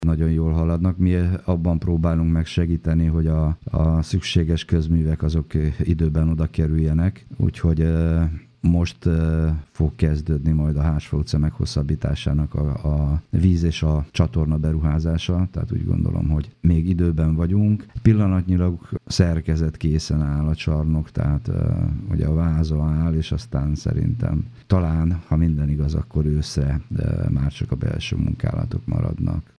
Dr. Gál Imre polgármestert hallják.